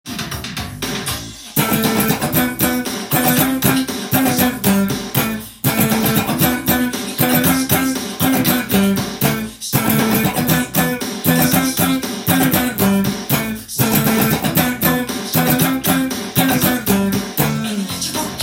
カッティングtab譜
譜面通り音源にあわせて弾いてみました